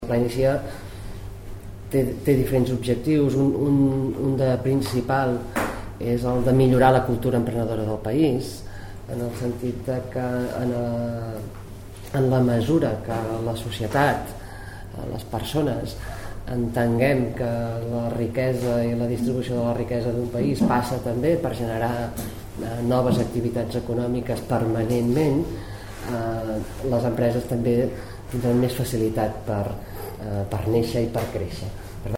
Tall de veu Mireia Franch, directora general d'Economia Cooperativa i Creació d'Empreses